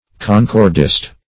Concordist \Con*cord"ist\, n. The compiler of a concordance.